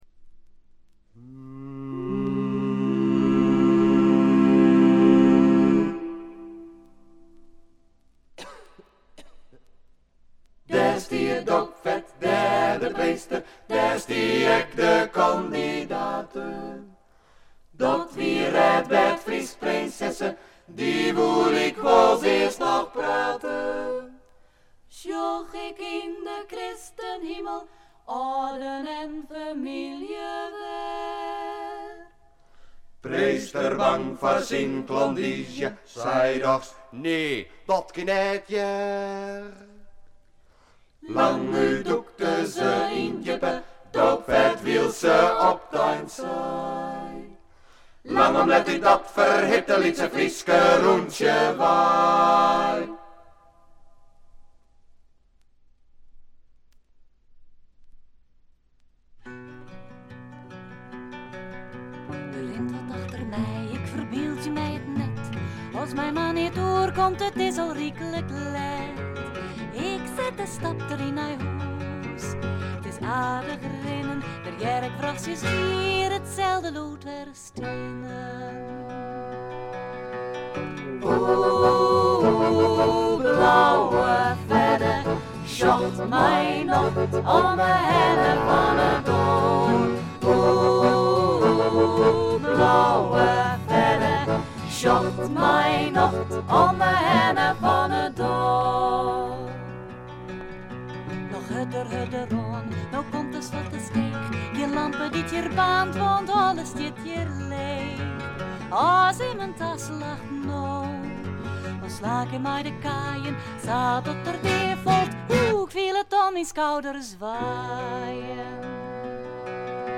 チリプチ少し。散発的なプツ音少し。
ダッチフォークの名バンド
試聴曲は現品からの取り込み音源です。